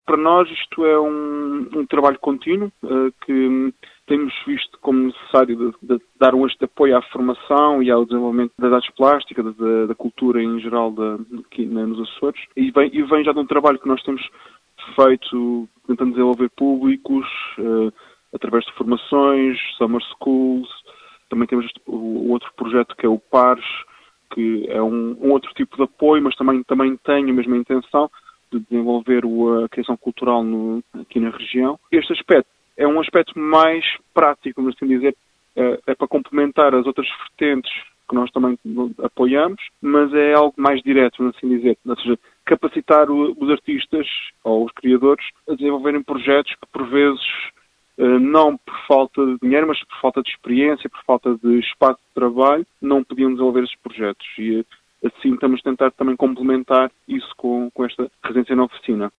em entrevista à Atlântida